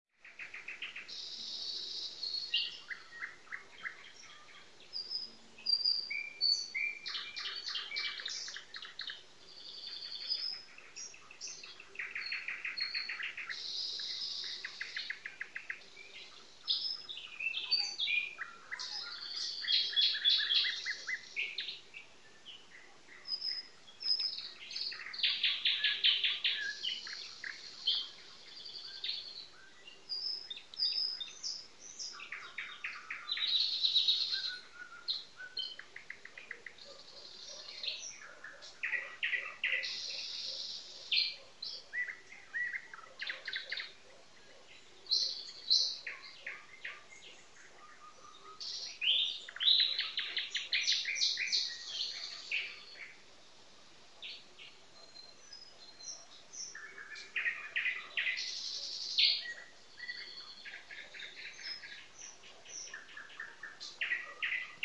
夜莺
描述：夜莺和安静的“青蛙无人机”。使用Android智能手机使用默认录制程序录制。地点：俄罗斯伏尔加格勒地区的一些森林。 2015年5月20日晚上。
Tag: 森林 自然 夜莺